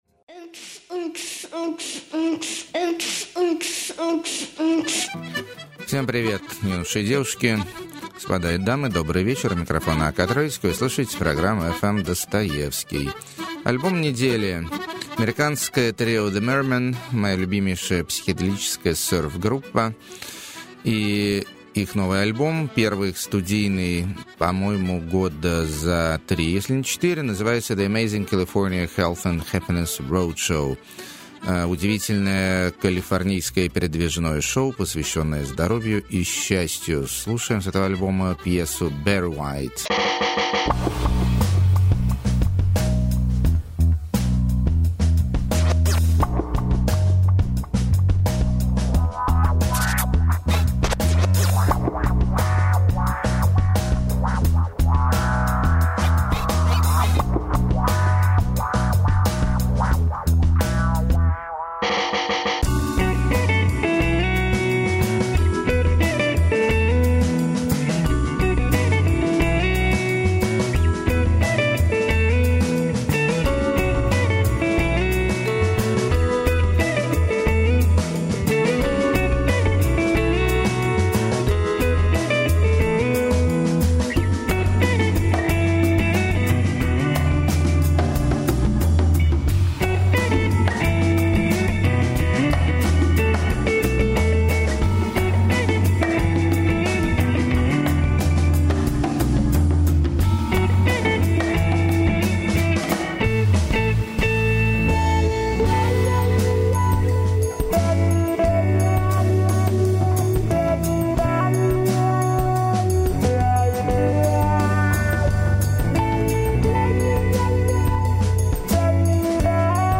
Сварливый Итальянский Шансон.
Современная Английская Молодежная Гитарная Музыка.
Циничная Полярная Электронная Декадентская Музыка.
Танцы Под Компьютер И Сёрф-гитарку.